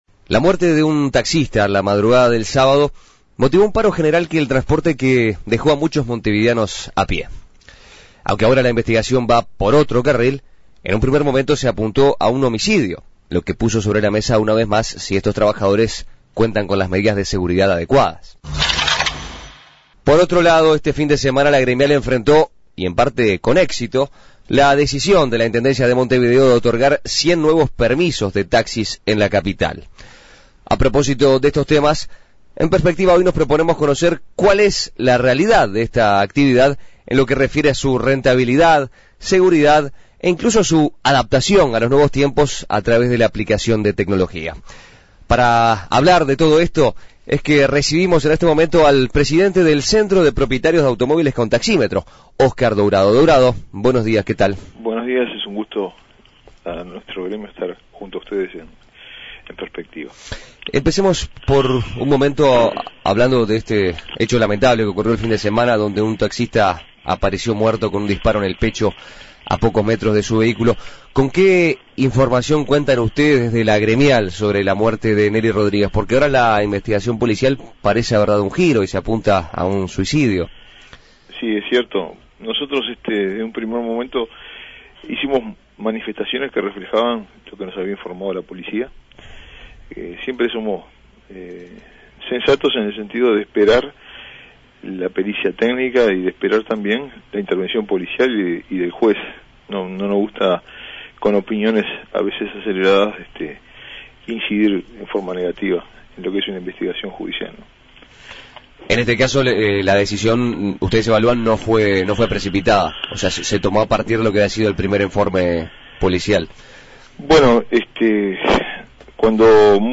Entrevistas